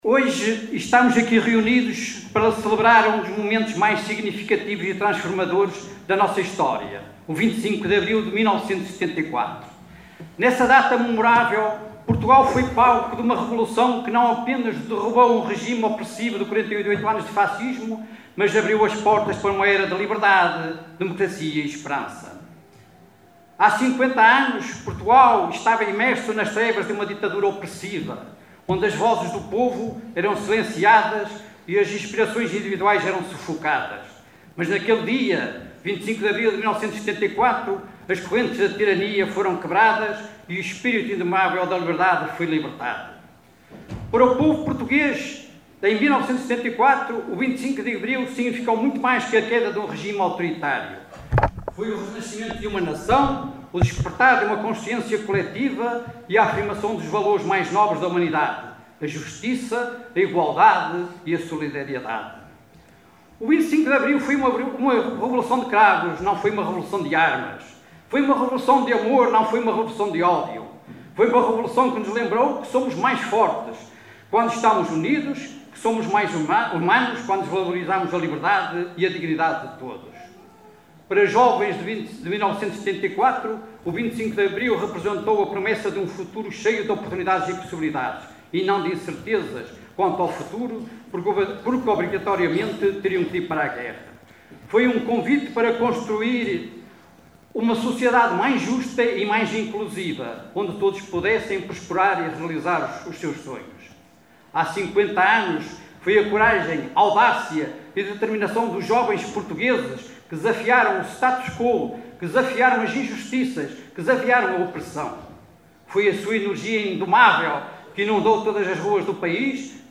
Manuel Martins Discurso
Discurso do presidente da Assembleia Municipal, Luís Martins, na sessão solene da Assembleia Municipal comemorativa dos 50 anos da revolução de Abril que decorreu ontem no Teatro Valadares em Caminha.